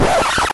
recordscratch.wav